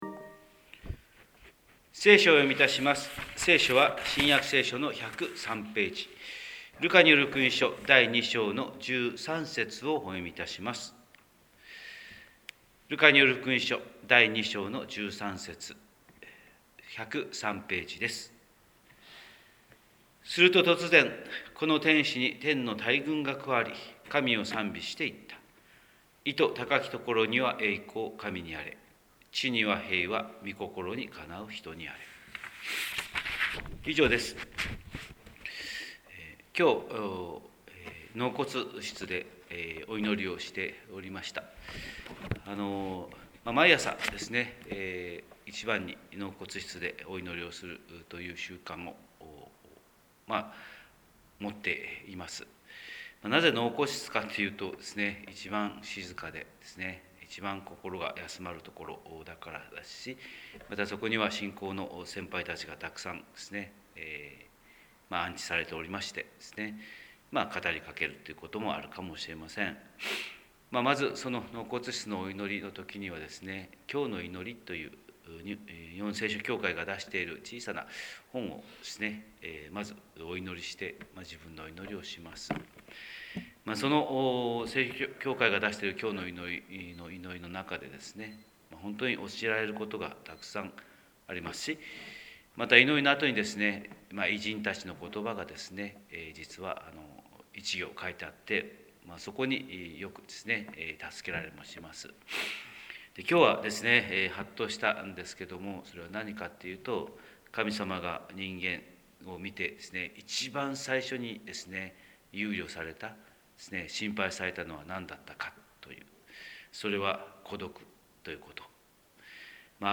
神様の色鉛筆（音声説教）: 広島教会朝礼拝250117
広島教会朝礼拝250117「孤独ではない」